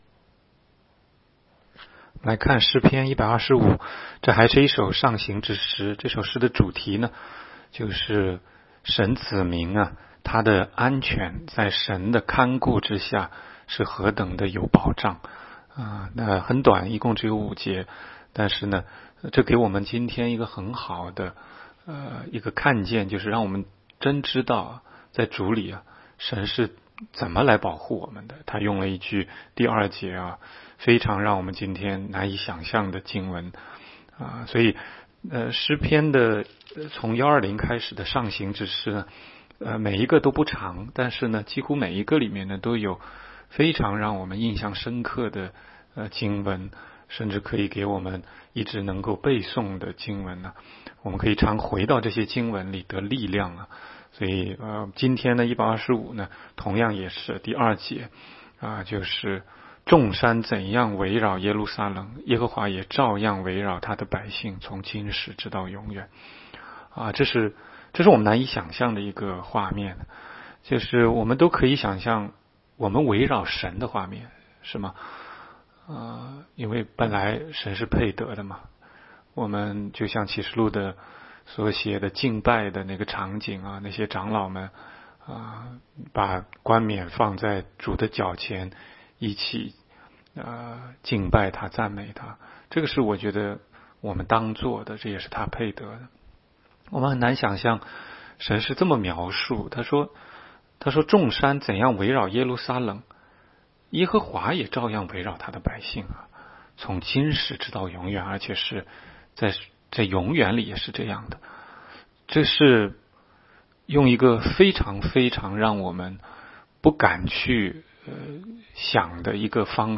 16街讲道录音 - 每日读经 -《 诗篇》125章